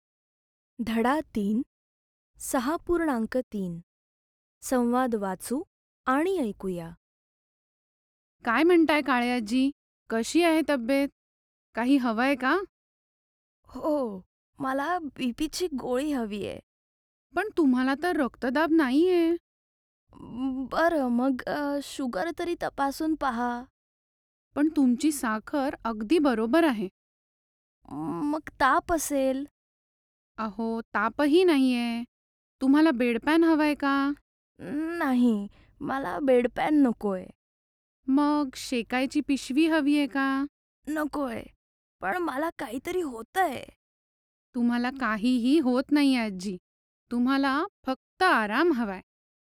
संवाद वाचू आणि ऐकू या. ६.३ संवाद वाचू आणि ऐकू या. ७.१ गाळलेल्या जागांमध्ये योग्य आकडा लिहा. भरत आला परत For better performance use Google Chorme COMMUNICATIVE MARATHI FOR NURSES